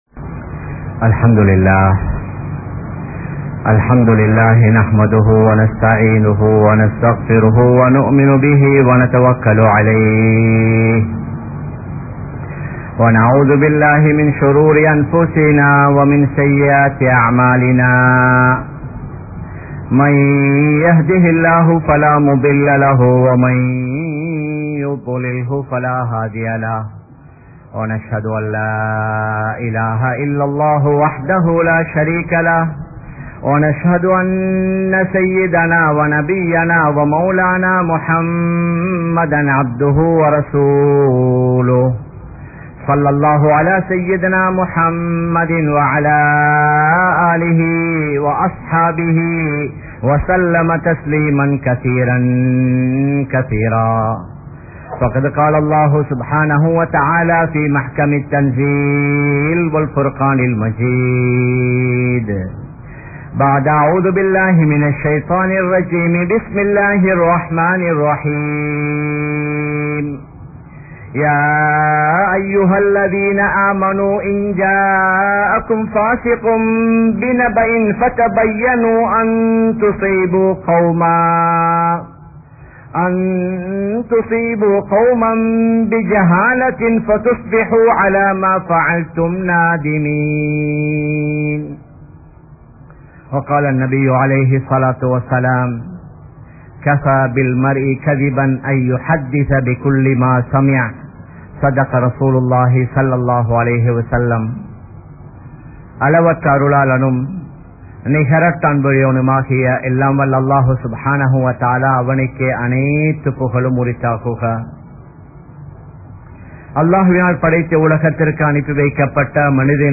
Social Media vai Evvaaru Paavippathu? (சமூக வலையதளங்களை எவ்வாறு பாவிப்பது?) | Audio Bayans | All Ceylon Muslim Youth Community | Addalaichenai
Kollupitty Jumua Masjith